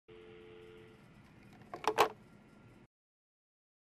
دانلود صدای قطع تلفن 1 از ساعد نیوز با لینک مستقیم و کیفیت بالا
جلوه های صوتی
برچسب: دانلود آهنگ های افکت صوتی اشیاء دانلود آلبوم صدای گذاشتن گوشی یا قطع تلفن از افکت صوتی اشیاء